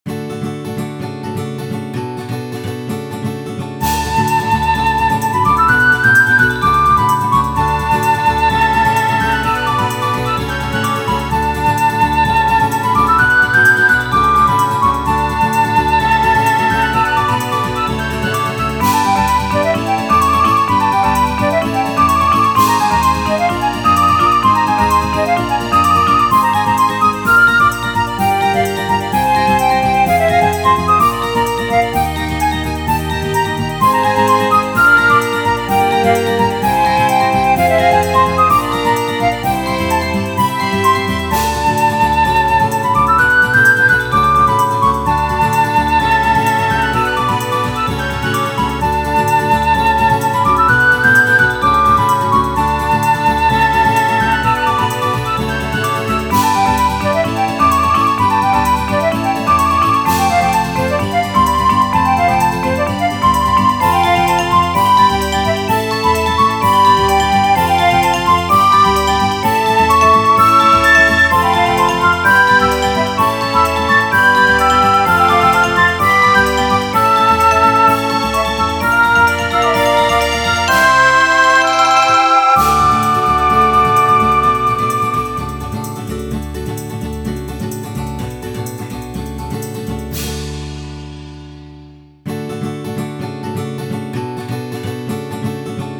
ogg(L) さわやか ギター 妖精楽隊
迷い込んだ旅人のギターと妖精楽隊のさわやかセッション。